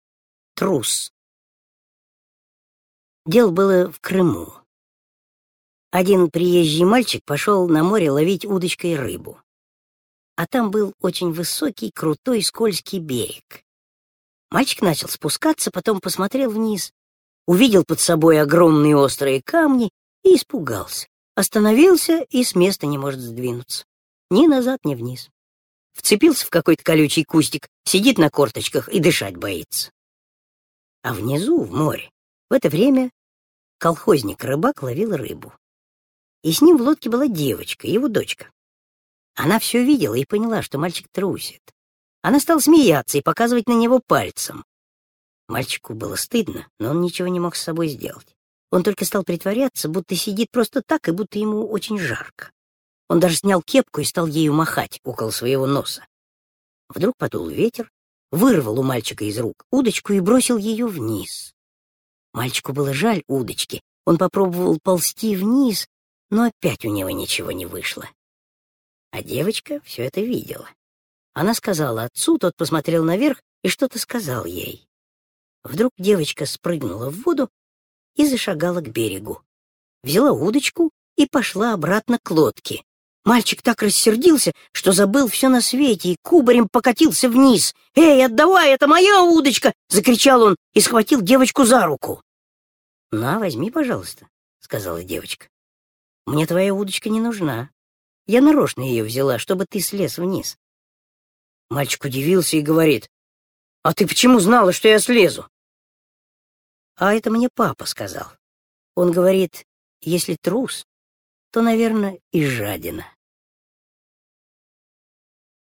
Аудиорассказ «Трус»